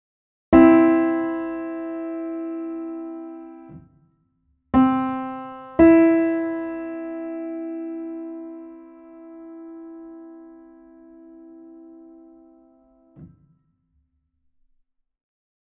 De afstand van een C naar een E bestaat uit vier halve noten, heet een grote terts en ervaren wij als prettig en vrolijk:
happy-2.mp3